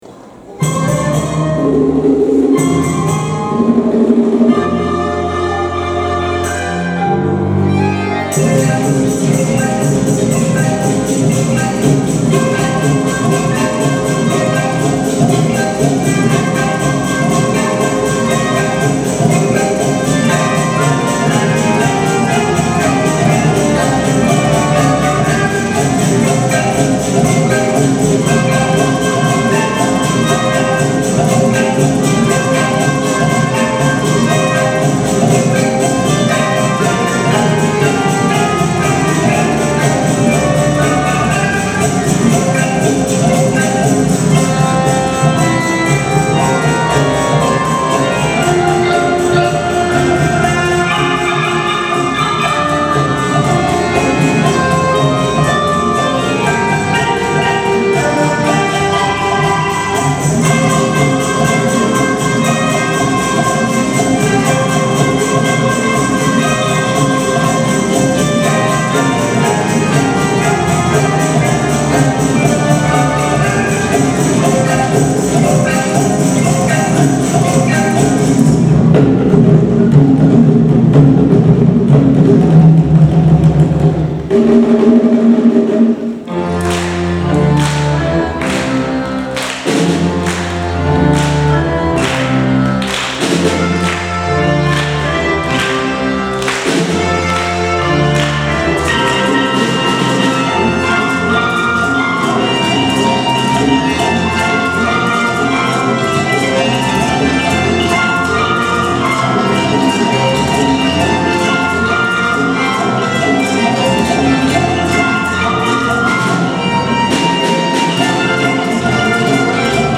今回は、この二つのラテンの曲をメドレーでつないで演奏しました。
ボンゴやコンガなどの楽器で、ラテンのリズムに乗った演奏でスタート！
高学年チームによるラテンの音楽が会場中に響き渡りました。
次の「ラ・バンバ」は子どもたちの手拍子で始まり、軽快なリズムで会場が盛り上がりました。